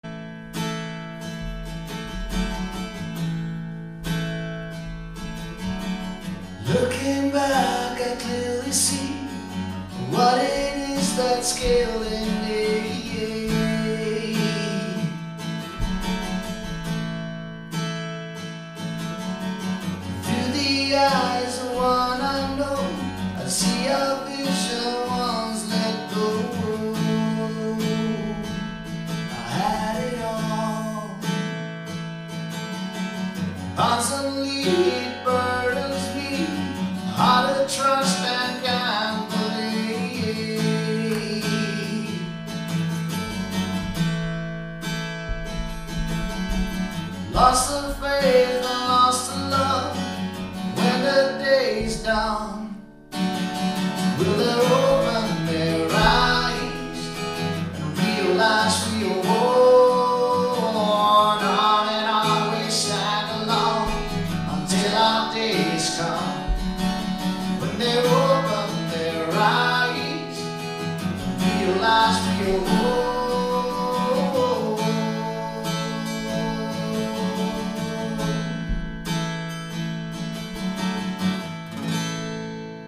• Solomusiker